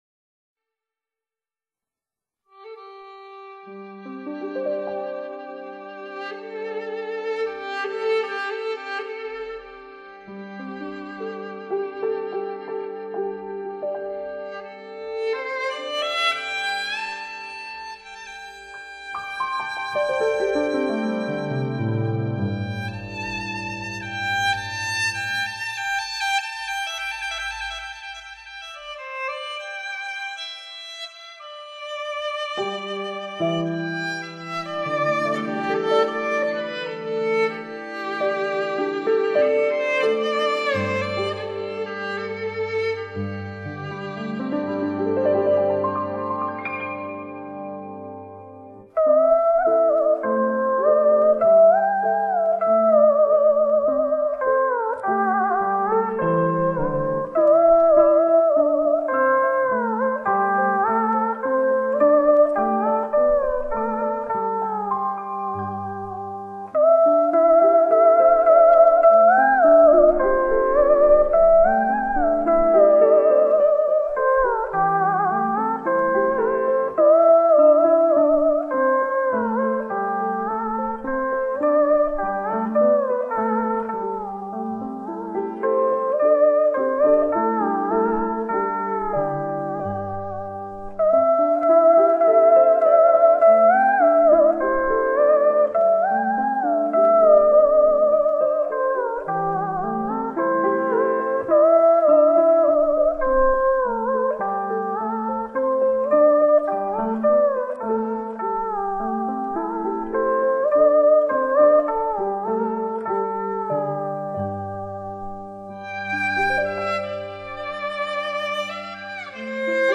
音乐类型:  民间音乐